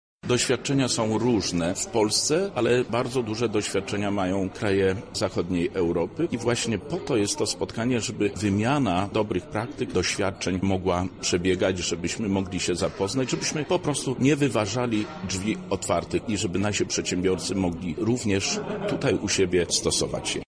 Możemy uczuć się wszyscy wzajemnie – mówi Zbigniew Wojciechowski, wicemarszałek Województwa Lubelskiego.